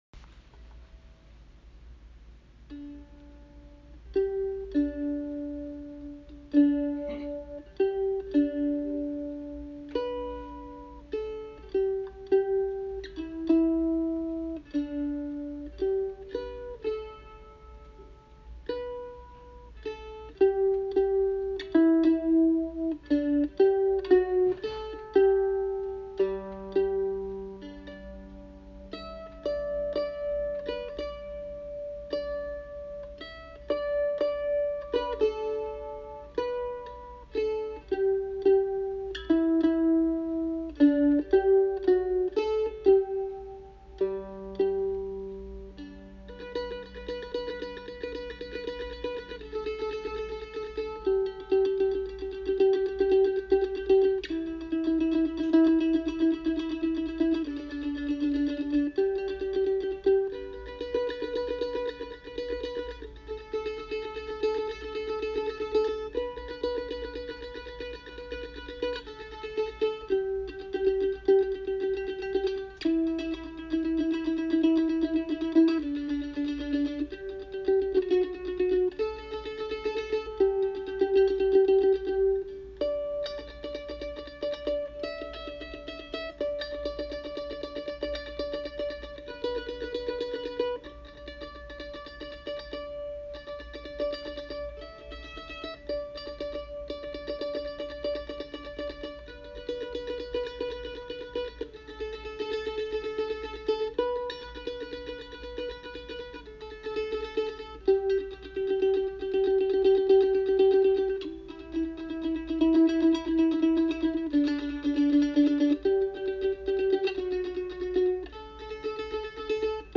This hymn is played on my Ratliff mandolin.
Nearer-my-God-to-Thee-mandolin-.mp3